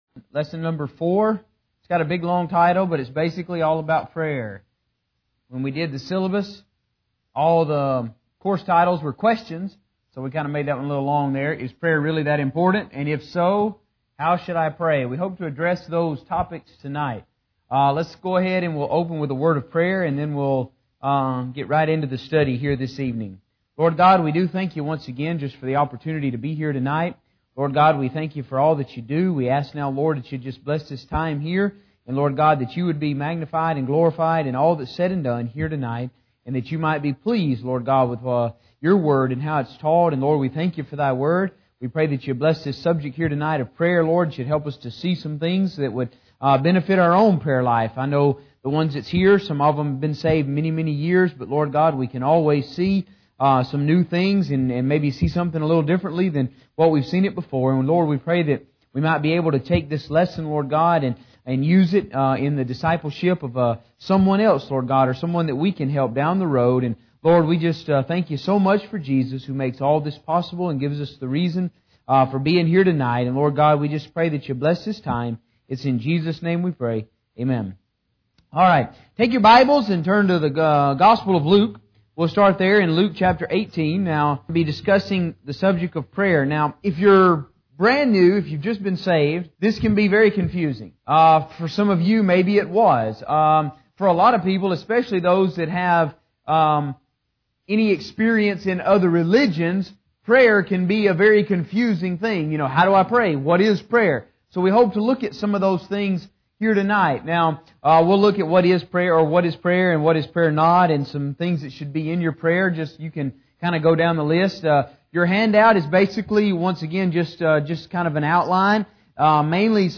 Discipleship Lesson #4 - Prayer - Bible Believers Baptist Church | Corpus Christi, Texas